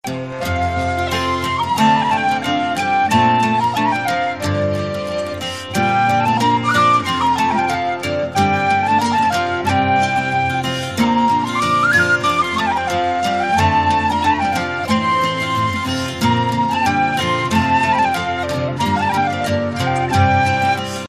cittern
Celtic harp